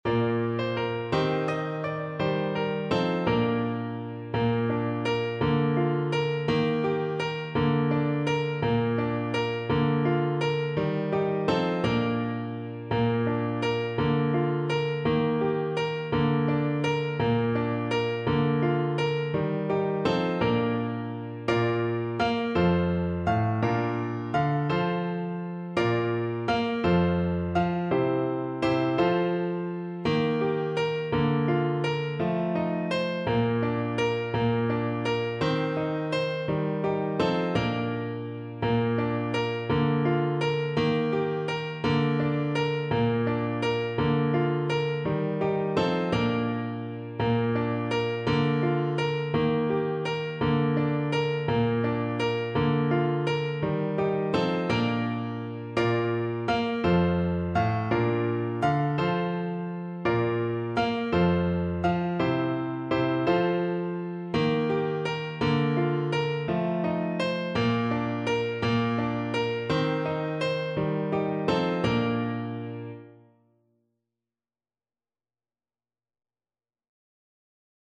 Clarinet
Traditional Music of unknown author.
6/8 (View more 6/8 Music)
Bb major (Sounding Pitch) C major (Clarinet in Bb) (View more Bb major Music for Clarinet )
Jolly .=c.56
blow_the_winds_CL_kar1.mp3